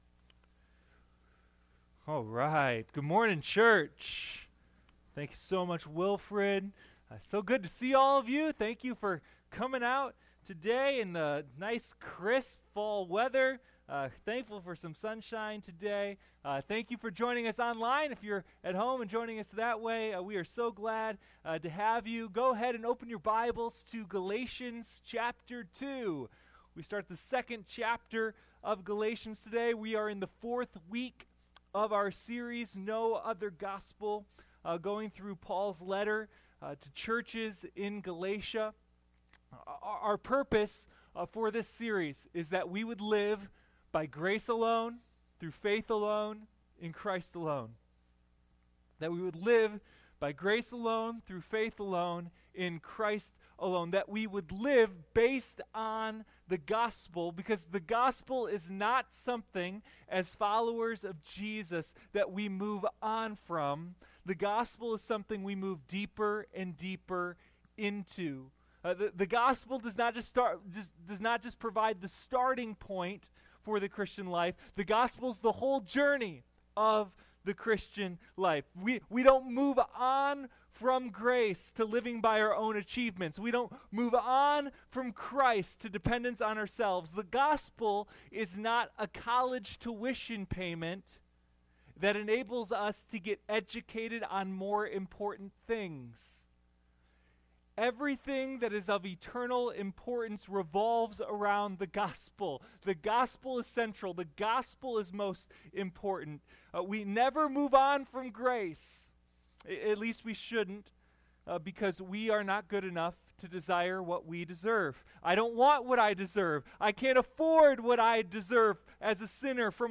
Sunday Morning No Other Gospel: A Study in Galatians